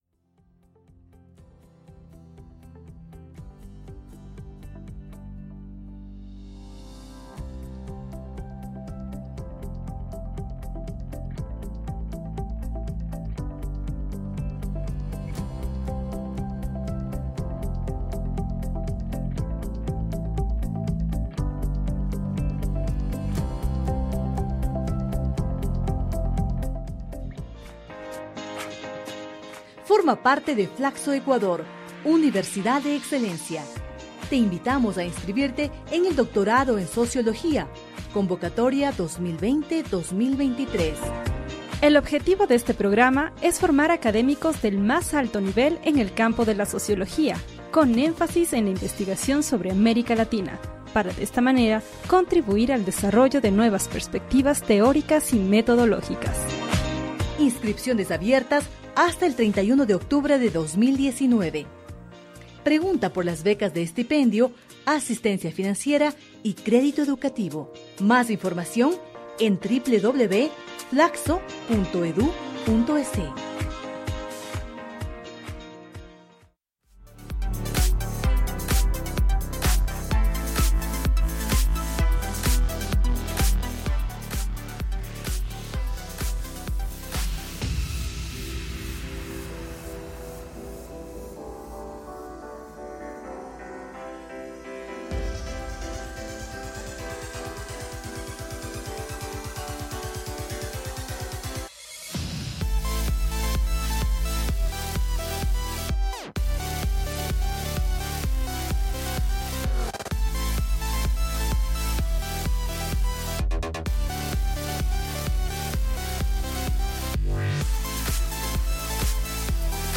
Ha iniciado un gran debate sobre este tema y hay quienes justifican su accionar y otros quienes señalan que vivimos en un estado de derecho y no deberíamos justificar el abuso policial, por ello en Studio Violeta escucharemos música contra la violencia policial, acompáñanos y participa con tus opiniones y comentarios.